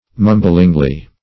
-- Mum"bling*ly , adv.